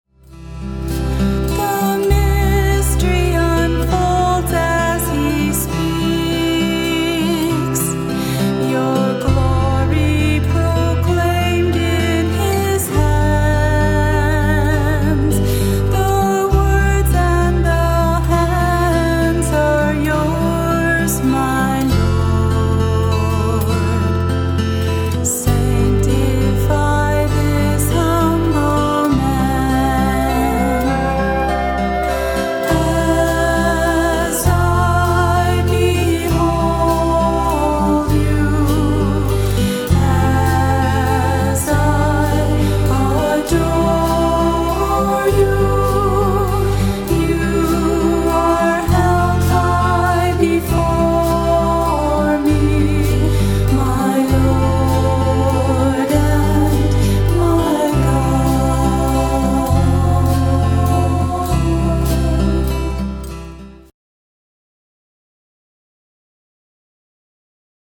The heart of my music ministry is praise and worship!